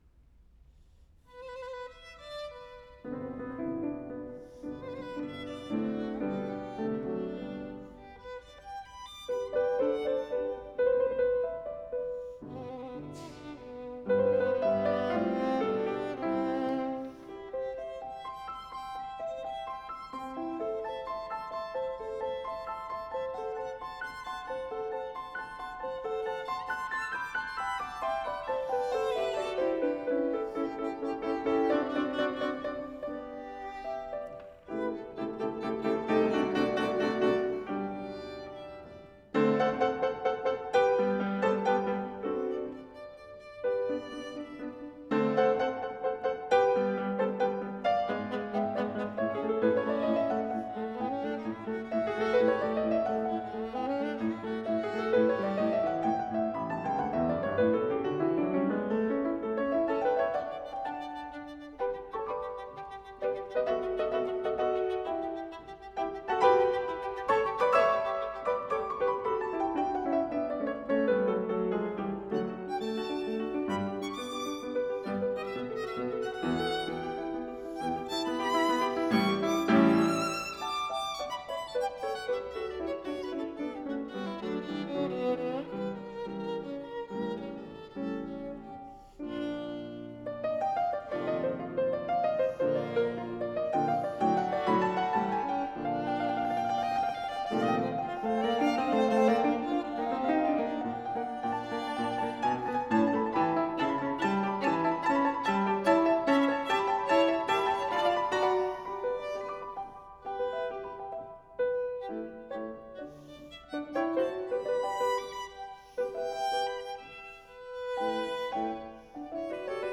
OK so here's the 3 separate mic pairs, raw, straight off the F8:
First the DPA 2011s in the main array.